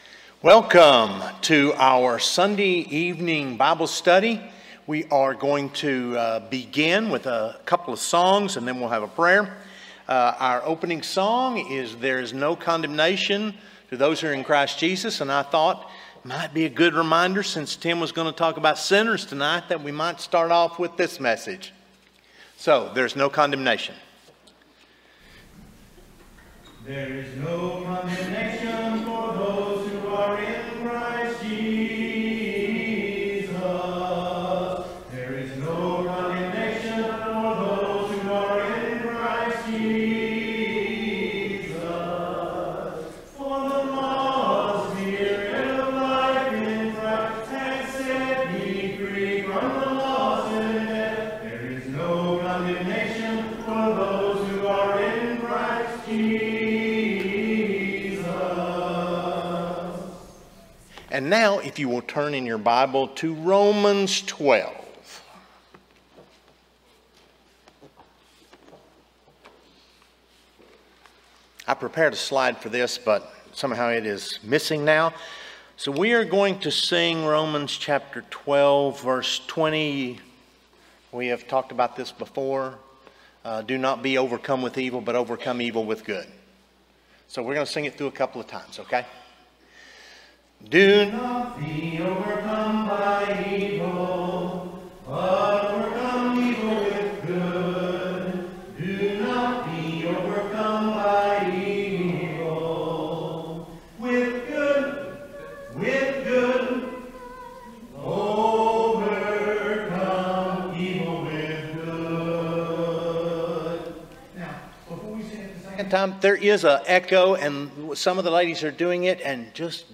Matthew 9:38, English Standard Version Series: Sunday PM Service